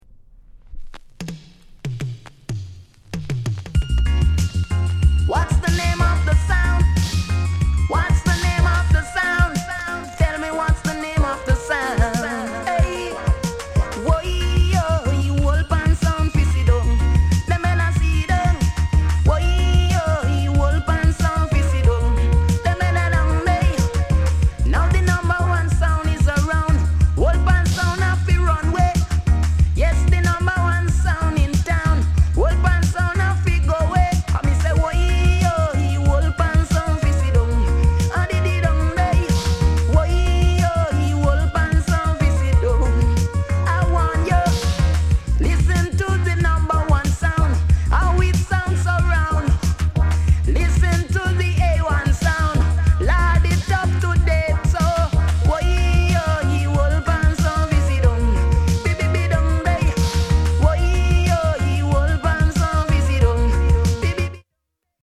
DIGI